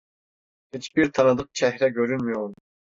Pronounced as (IPA) /t͡ʃeh.ɾe/